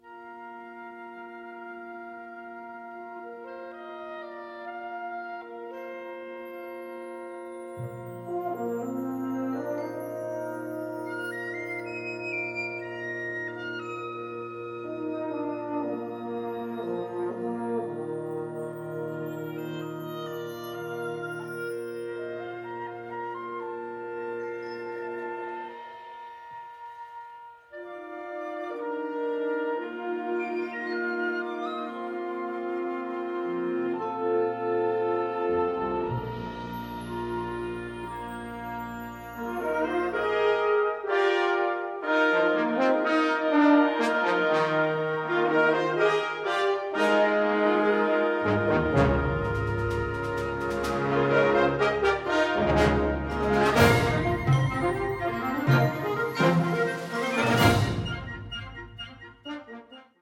Kategorie Blasorchester/HaFaBra
Unterkategorie Zeitgenössische Musik (1945-heute)
Besetzung Ha (Blasorchester)